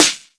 • Trebly Acoustic Snare D Key 92.wav
Royality free acoustic snare sample tuned to the D note. Loudest frequency: 4686Hz
trebly-acoustic-snare-d-key-92-Vzu.wav